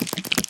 assets / minecraft / sounds / mob / spider / step4.ogg
step4.ogg